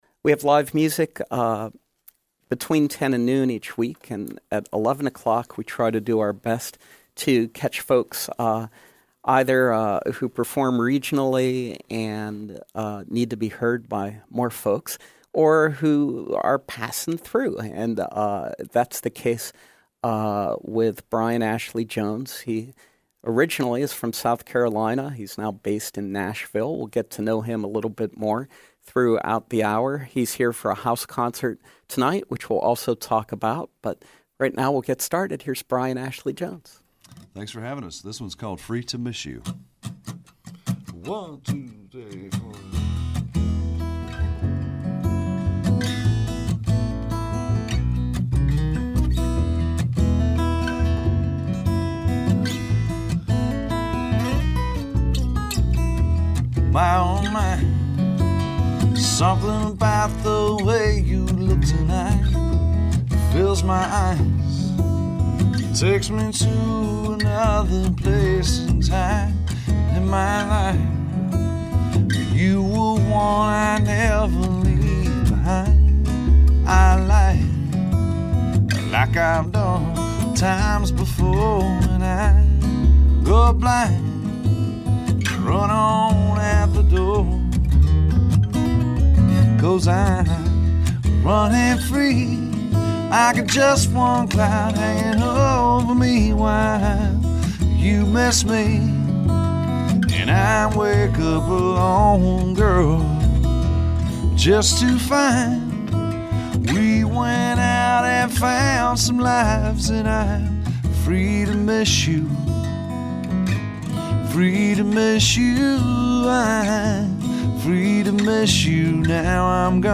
upright bass